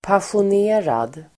Uttal: [pasjon'e:rad]